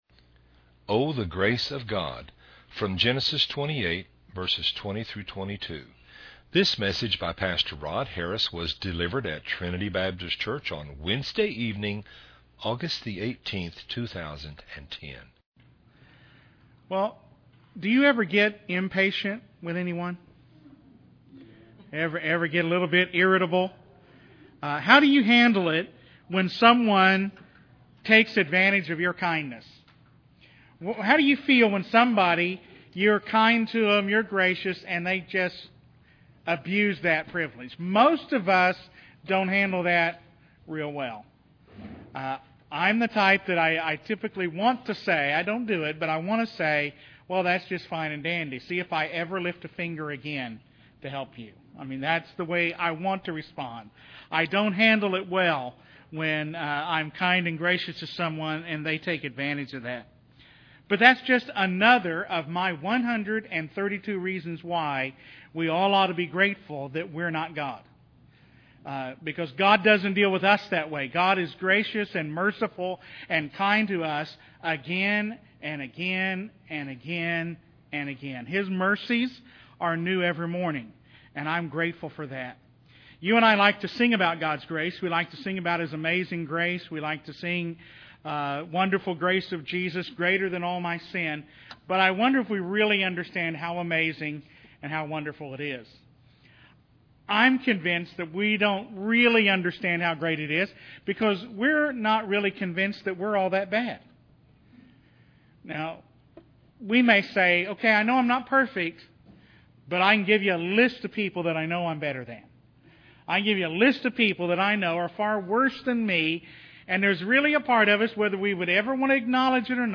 A Wednesday-evening Bible study from Genesis 28:20-22
delivered at Trinity Baptist Church on August 18